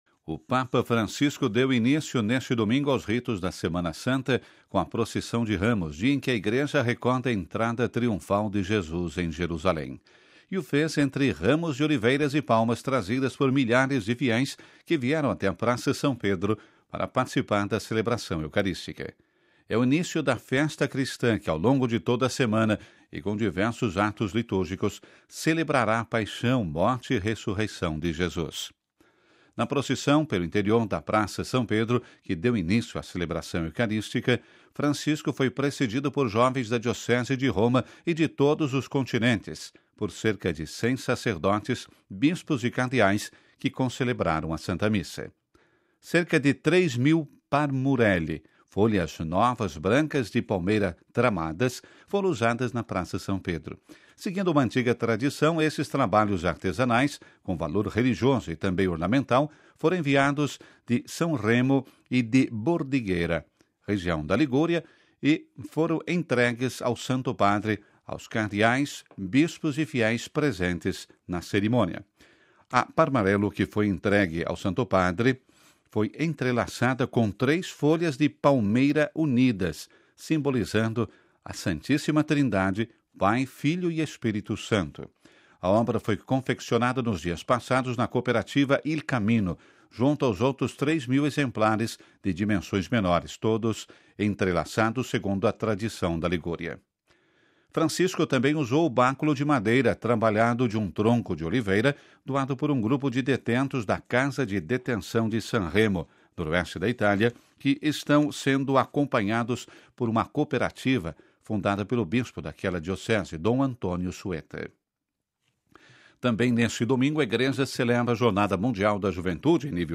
O Papa deixou de lado a sua homilia escrita e improvisou uma profunda reflexão recordando os personagens descritos na leitura do Evangelho deste domingo.
Francisco continua com as suas perguntas em meio a uma Praça silenciosa e reflexiva.